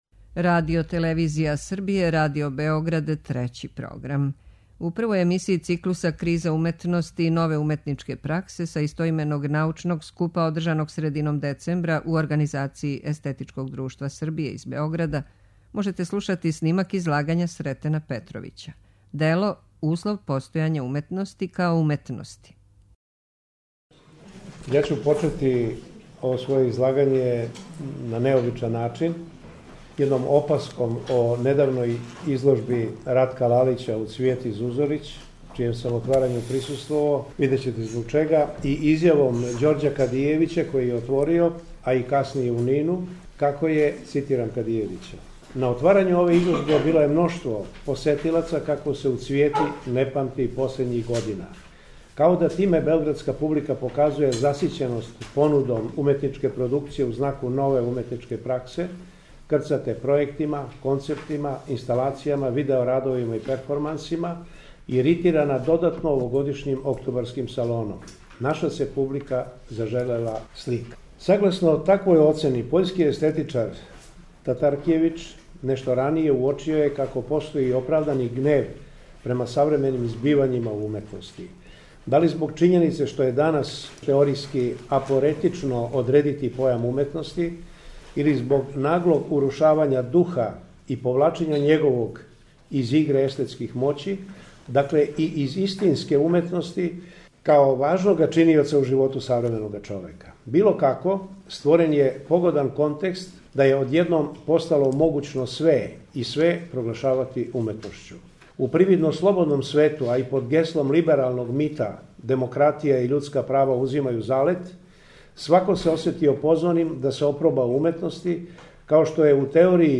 У две вечерашње емисије, којима почињемо циклус КРИЗА УМЕТНОСТИ И НОВЕ УМЕТНИЧКЕ ПРАКСЕ, можете пратити снимке излагања са истоименог научног скупа одржаног средином децембра у организацији Естетичког друштва Србије.
Научни скупови